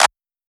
TM88 - CLAP (1).wav